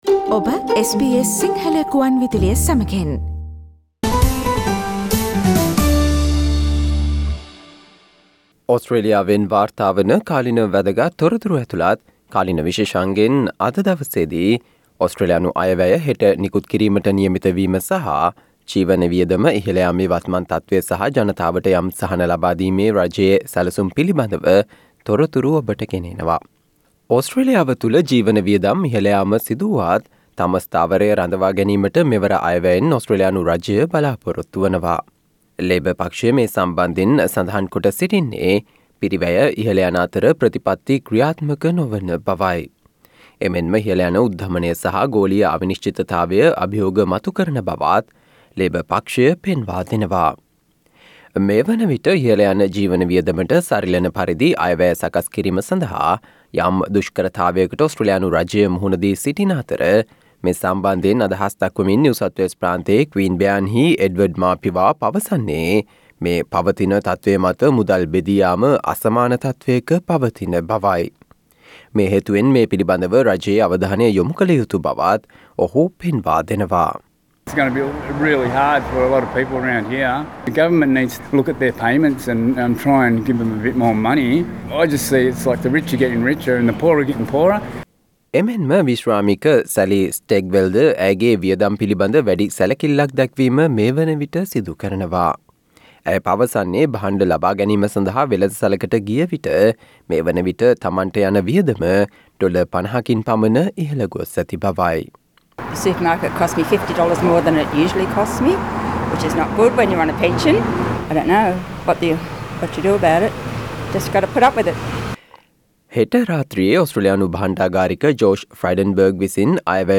මාර්තු 28 වන දා සඳුදා ප්‍රචාරය වූ SBS සිංහල සේවයේ කාලීන තොරතුරු විශේෂාංගයට සවන්දෙන්න.